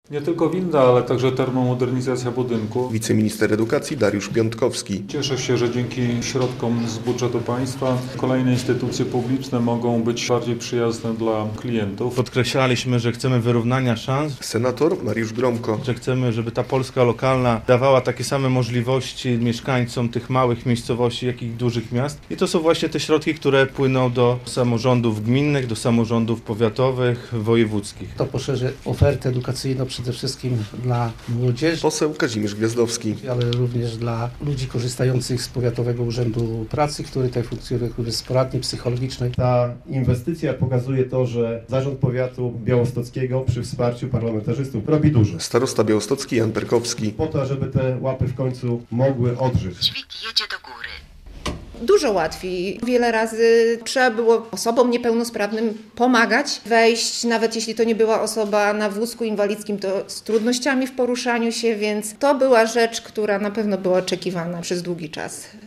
Internat w Łapach już po przebudowie - relacja